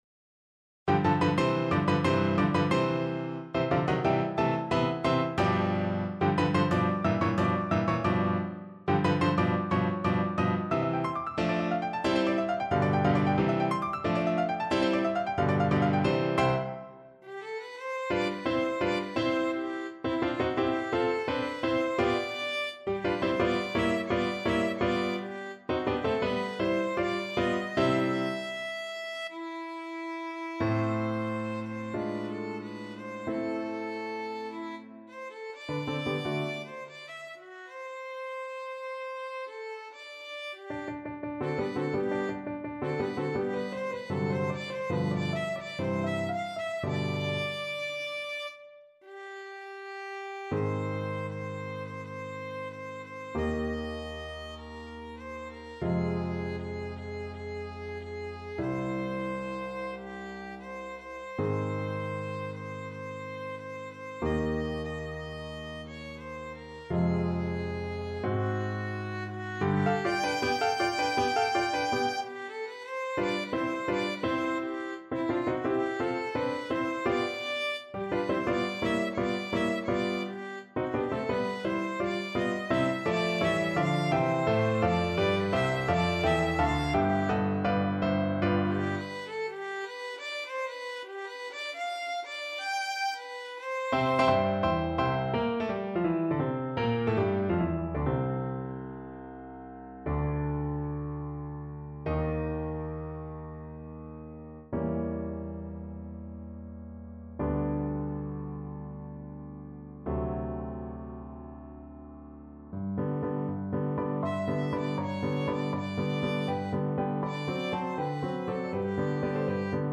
Classical Donizetti, Gaetano Ah! mes amis, quel jour de fete from La fille du regiment Violin version
Play (or use space bar on your keyboard) Pause Music Playalong - Piano Accompaniment transpose reset tempo print settings full screen
Violin
C major (Sounding Pitch) (View more C major Music for Violin )
2/4 (View more 2/4 Music)
= 90 Allegro Vivace (View more music marked Allegro)
Classical (View more Classical Violin Music)